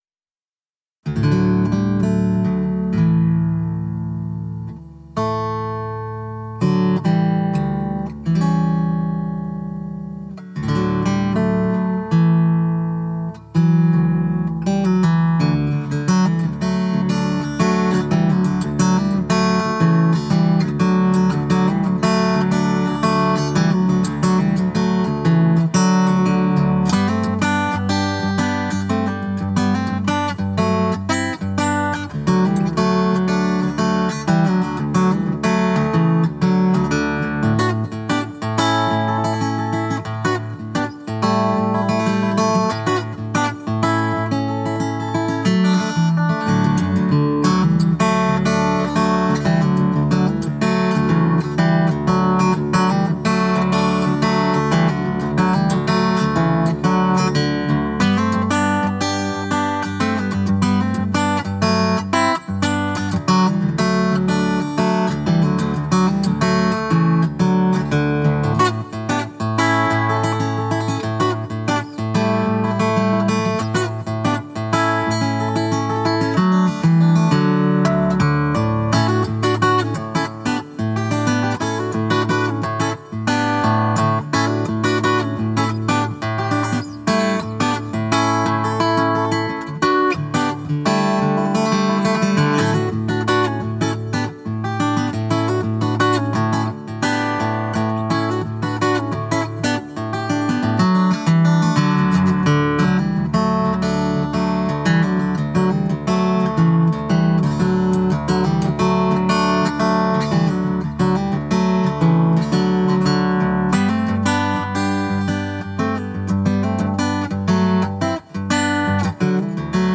Vancouver Guitarist